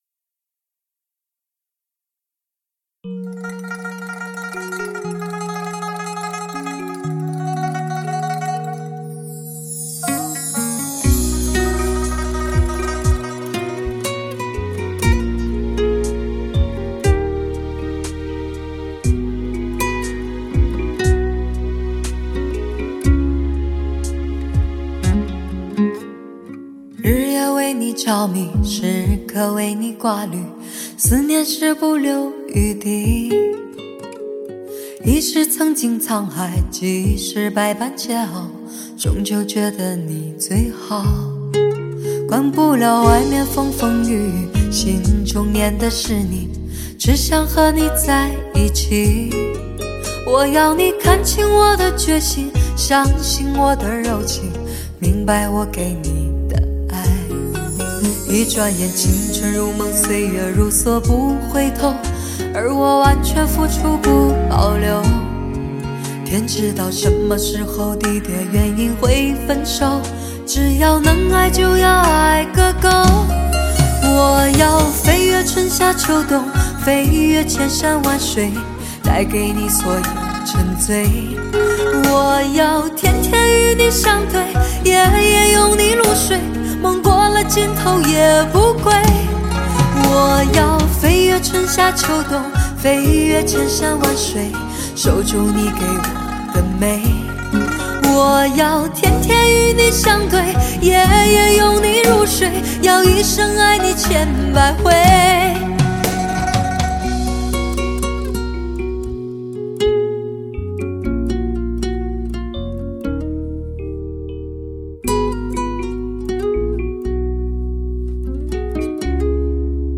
穿透灵魂的磁性女声 抚慰情殇
“灵魂女声”--HIFI的“第一诱惑”